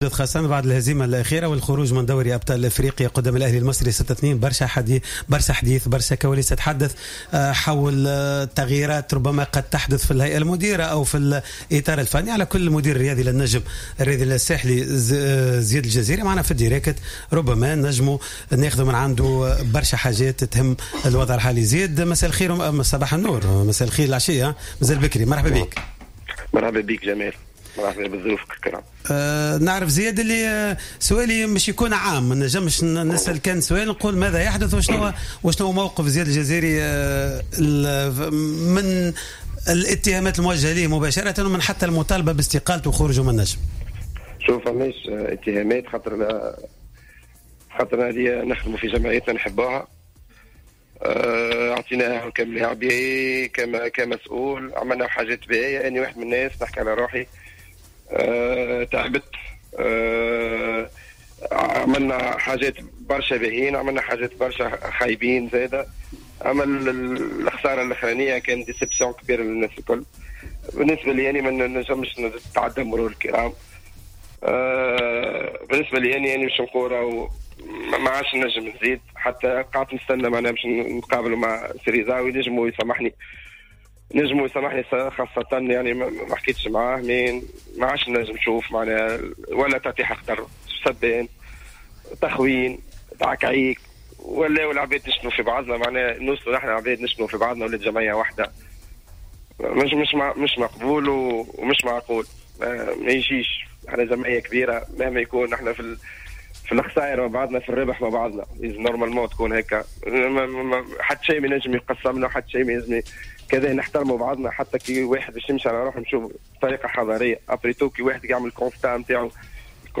و أكد الجزيري أنه قد إتخذ قراره بعد تفكير عميق و لا يعتبر ذلك هروبا من المسؤولية لكنه يرى أنه قام بواجبه على أكمل وجه تجاه فريقه ولم يعد قادرا على المواصلة في ظل الظروف الحالية .* تصريح زياد الجزيري بصفة حصرية لجوهرة أف أم :